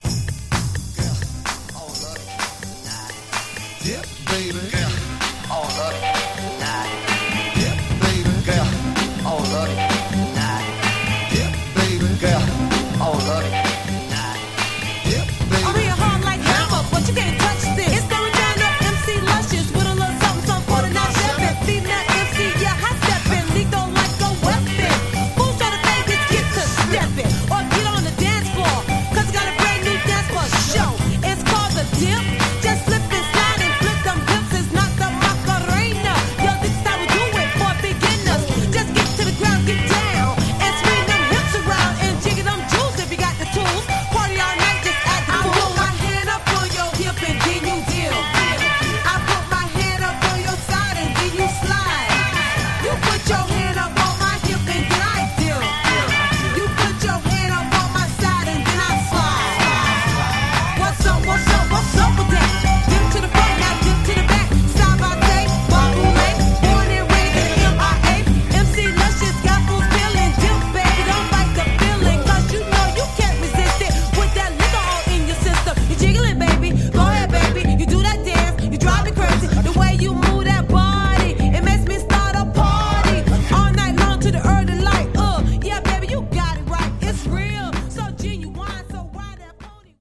127 bpm